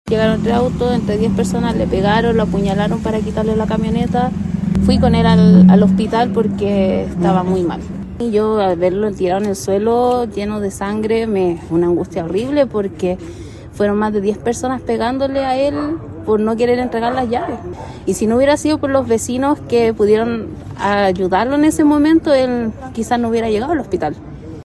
Una familiar de la víctima y una testigo relataron que fueron alrededor de 10 delincuentes los que perpetraron el ilícito, y que la víctima se resistió a entregar las llaves de su auto.